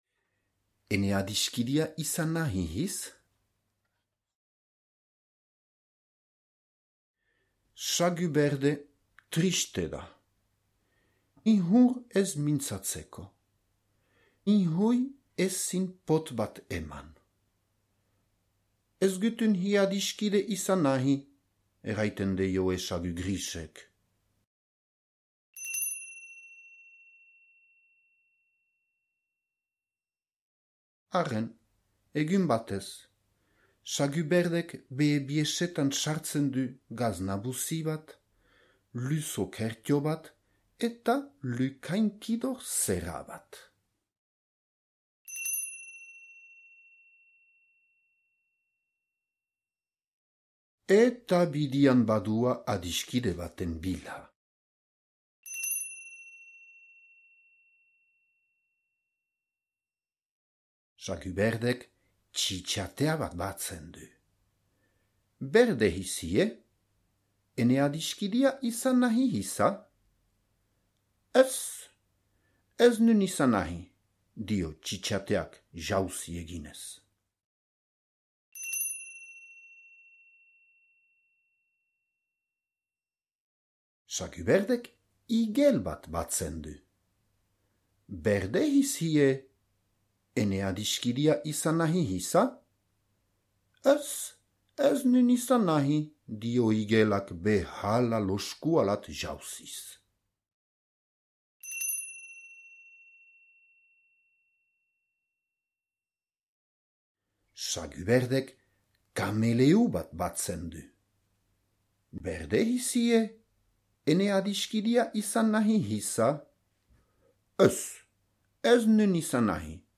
Ene adiskidea izan nahi hiz? - zubereraz - ipuina entzungai